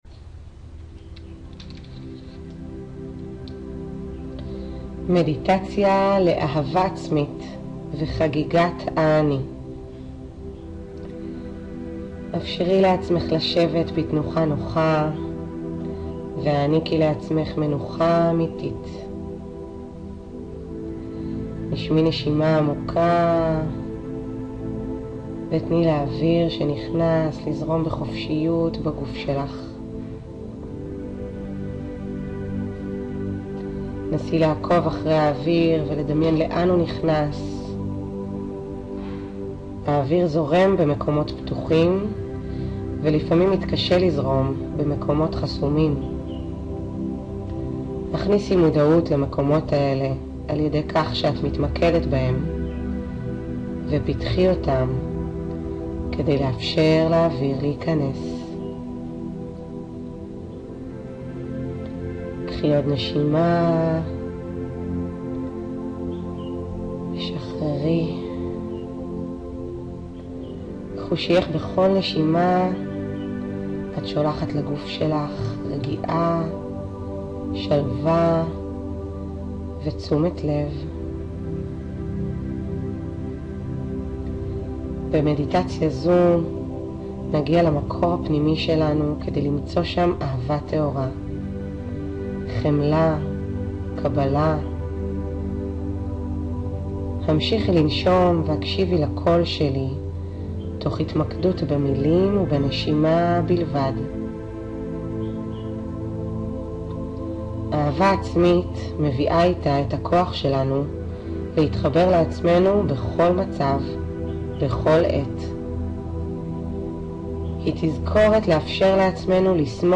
מדיטציה לאהבה עצמית – 9 דק'